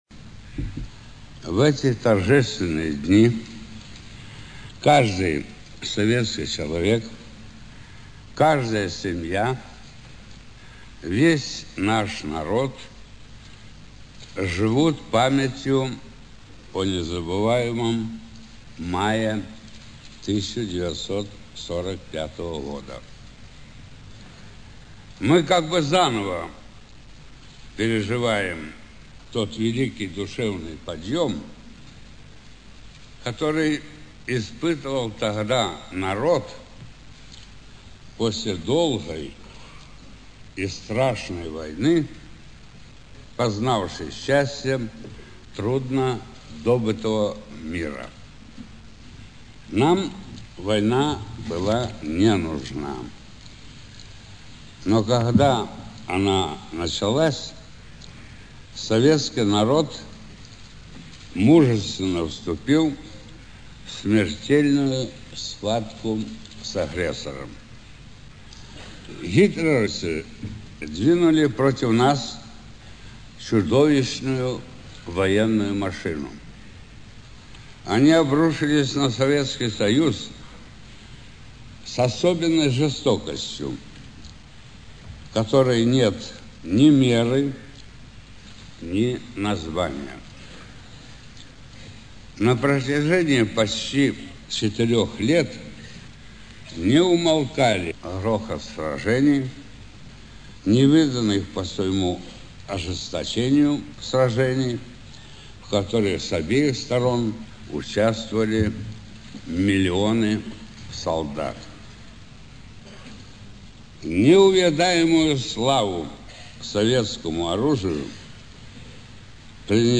В Кремлевском Дворце съездов 8 мая 1975 года состоялось торжественное собрание, посвященное 30-летию Победы советского народа в Великой Отечественной войне
Выступление Генерального секретаря ЦК КПСС Леонида Ильича Брежнева
С докладом на нем выступил Генеральный секретарь ЦК КПСС Леонид Ильич Брежнев.
Аудиозапись выступления (1,5 Мбайт)